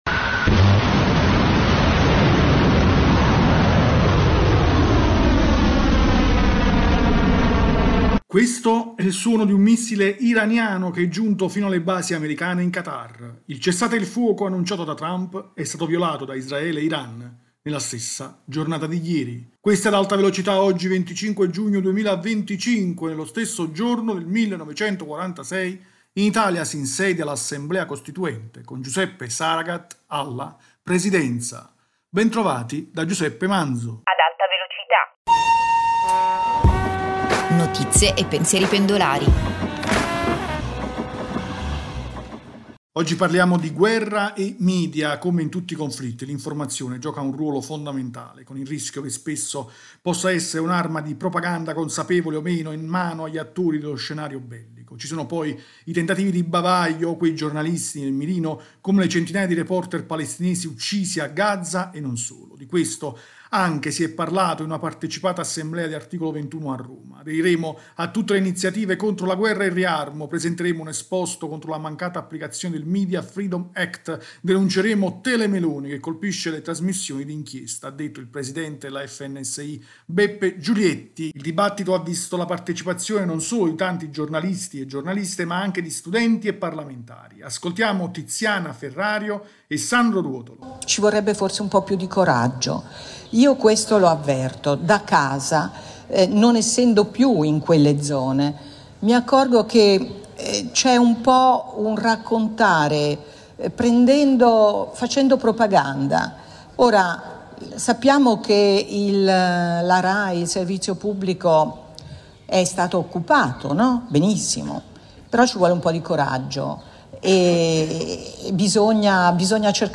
Ascoltiamo Tiziana Ferrario e Sandro Ruotolo.